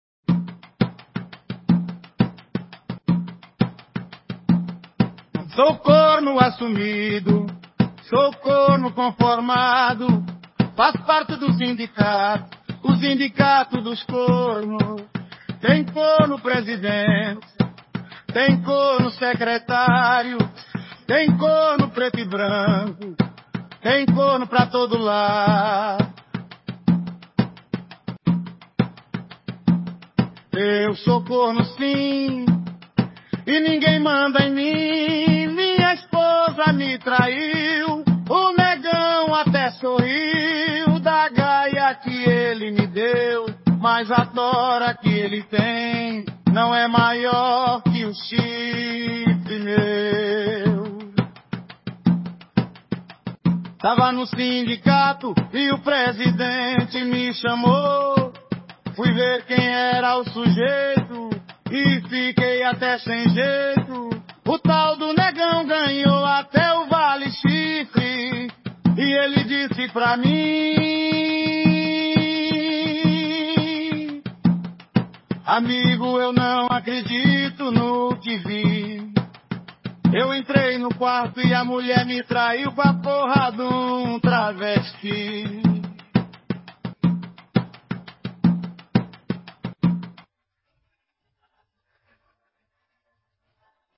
Música, Engraçadas Repente do Corno Mande para aquele seu amigo que logo fará parte desse sindicato.